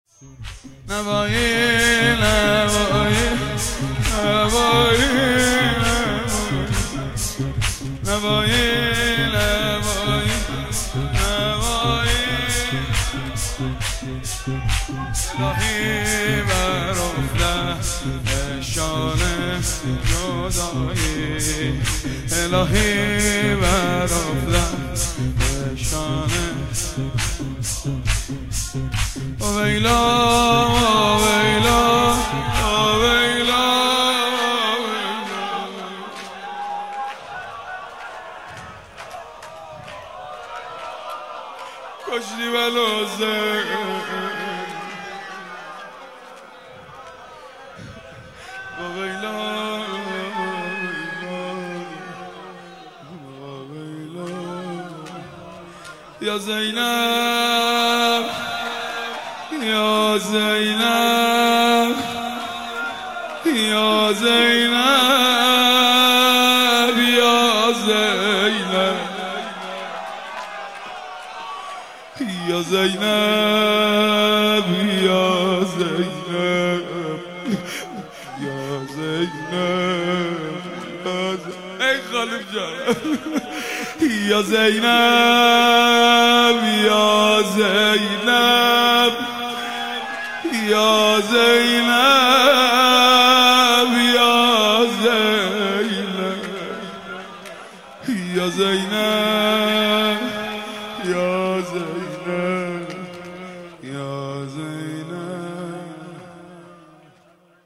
13-Shoor-2.mp3